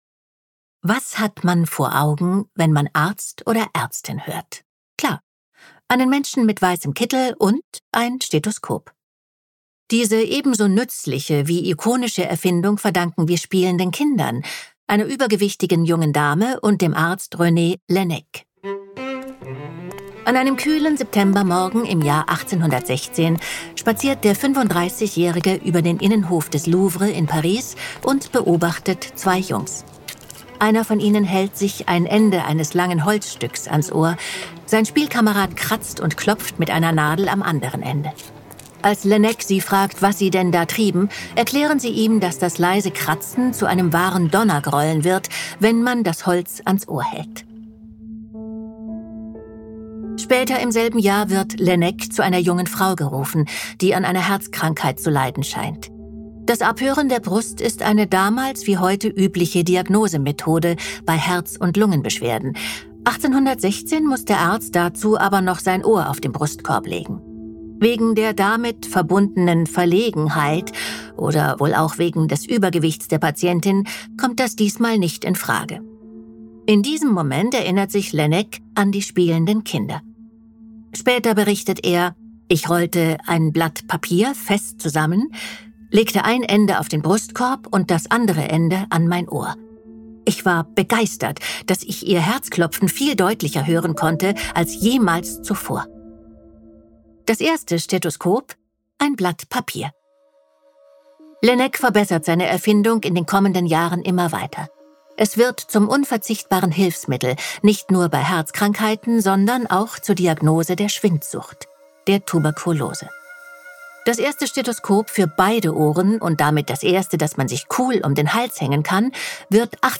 Warum Tuberkulose eine der gefährlichsten Infektionskrankheiten ist und weshalb sie bis heute nicht besiegt werden konnte, erzählt Andrea Sawatzki in dieser Folge.